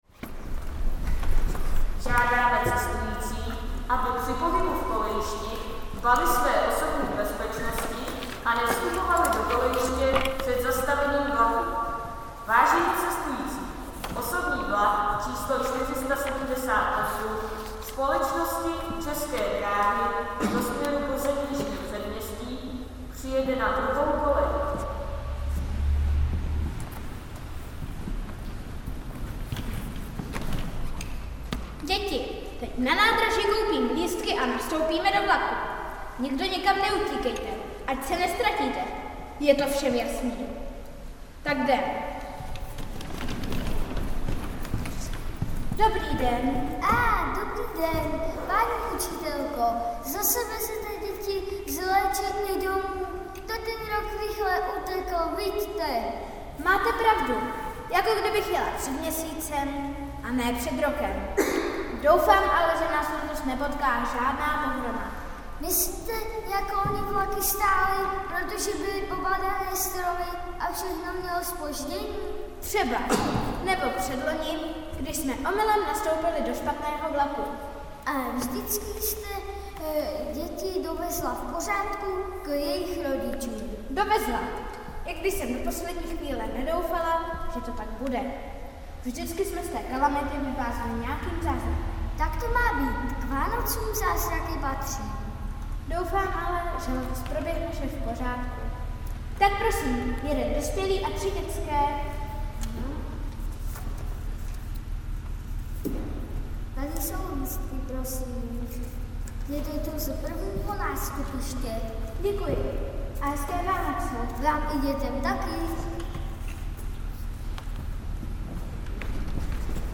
Rodinná neděle
V průběhu bohoslužeb proběhla derniéra dětské vánoční hry „Vánoční vlak aneb Co je zázrak“.
audio záznam 1. části hry